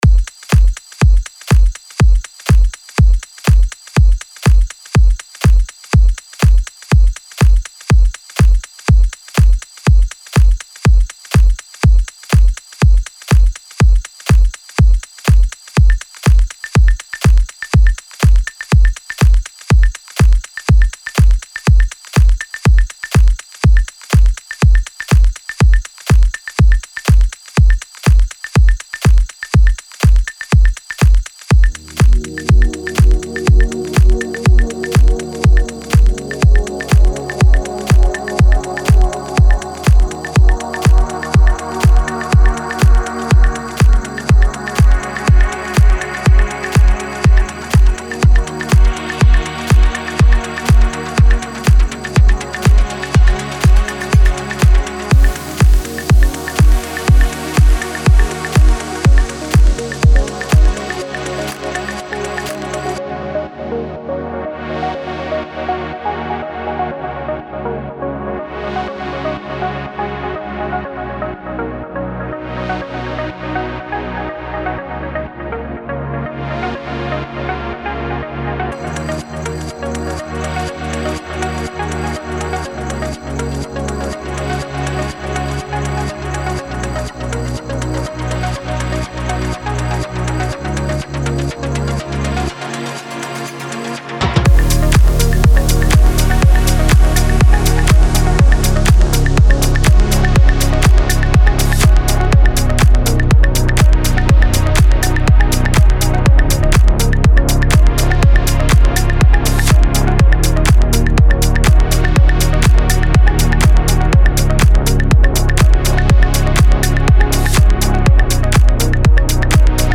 Deep House House